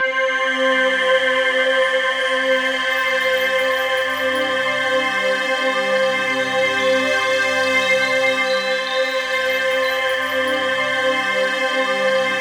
TUBULARC4.-L.wav